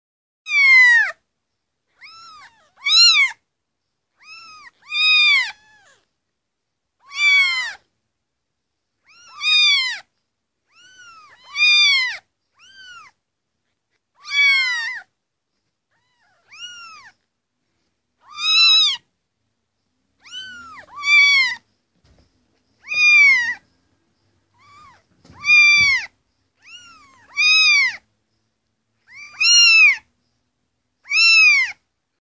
8. Пищат котята